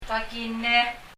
kakine [kakine] 日本語の垣根（かきね）から来ているのでしょう。